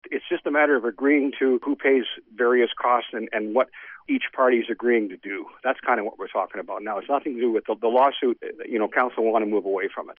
Jangula says discussions continue on various cost issues…